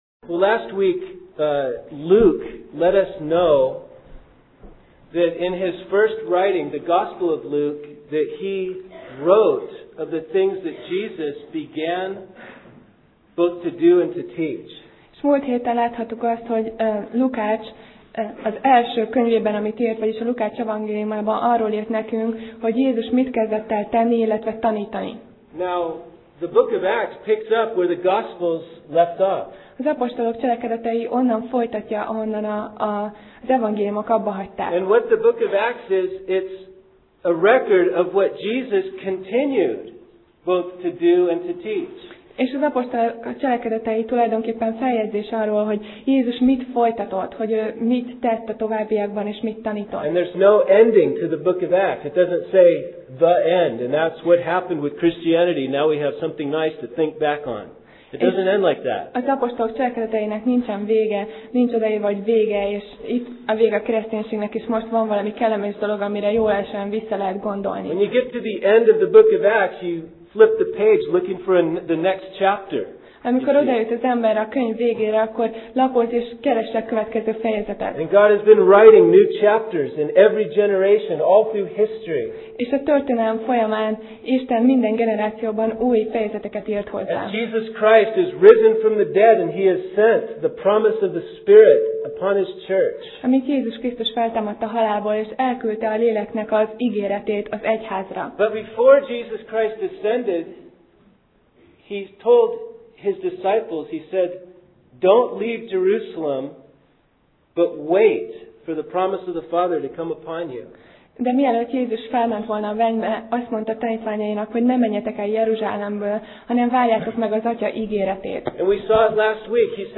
Sorozat: Apostolok cselekedetei Passage: Apcsel (Acts) 1:9-26 Alkalom: Vasárnap Reggel